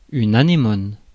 Ääntäminen
Synonyymit anémone de mer actinie Ääntäminen France: IPA: /a.ne.mɔn/ Haettu sana löytyi näillä lähdekielillä: ranska Käännöksiä ei löytynyt valitulle kohdekielelle.